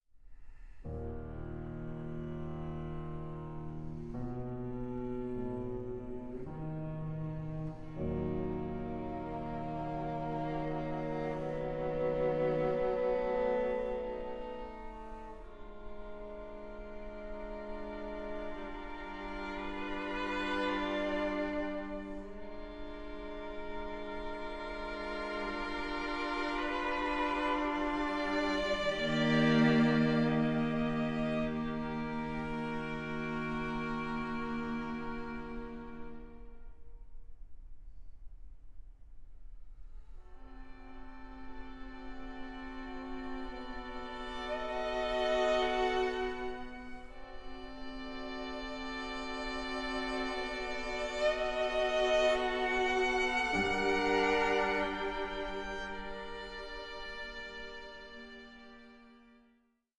for violin, piano and string quartet